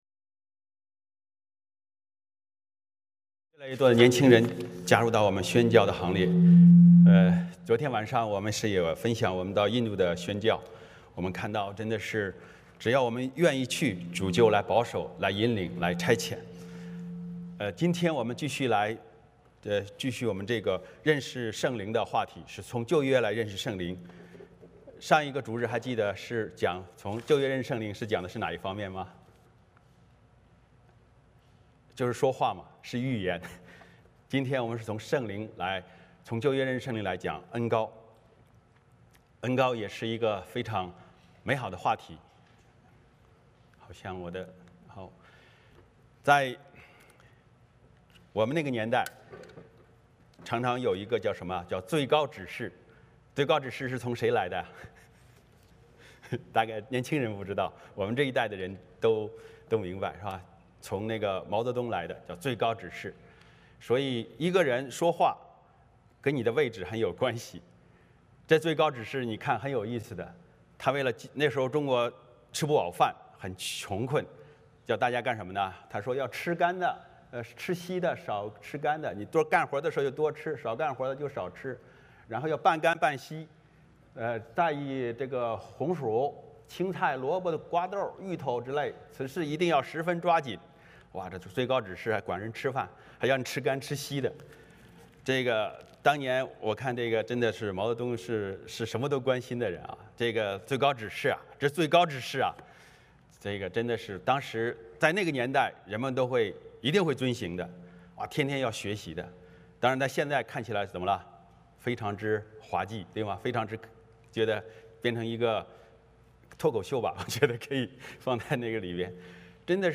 11:1 Service Type: 主日崇拜 欢迎大家加入我们的敬拜。